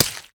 Flesh Hit.wav